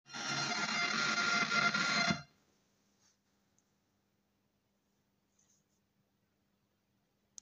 Störgeräusche beim Röhrenverstärker Bugera333XL
Hi an alle, habe einen Bugera 333XL Röhrenverstärker bei mir stehen der laute Geräusche von sich gibt (hört mal rein). Die Geräusche erinnern mich an Rückkopplungen wie die wenn man mit einen Handy rangeht. Sie verschwinden meist nach einer gewissen Zeit und kommen dann wieder.